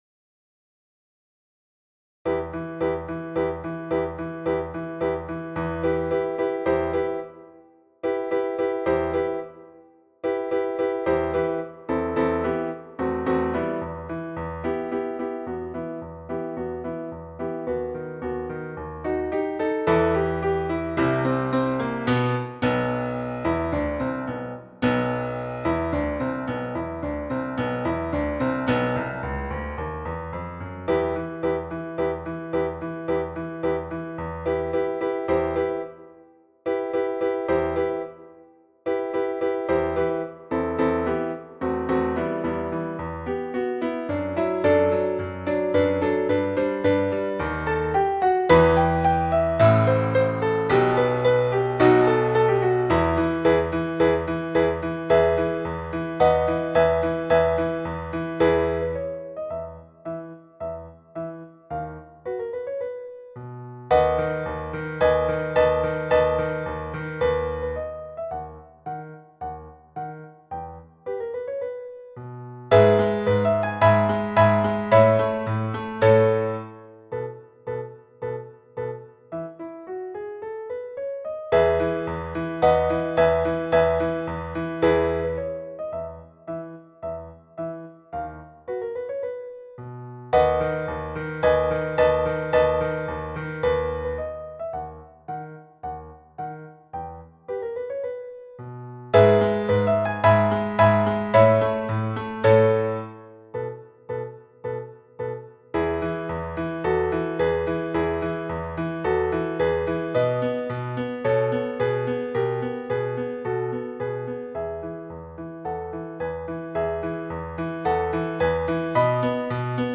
The Barber of Seville – piano à 109 bpm